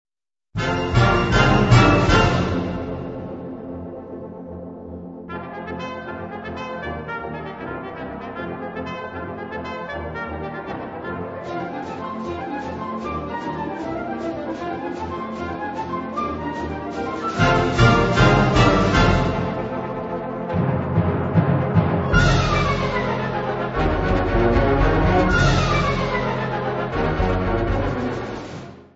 Catégorie Harmonie/Fanfare/Brass-band
Instrumentation Ha (orchestre d'harmonie)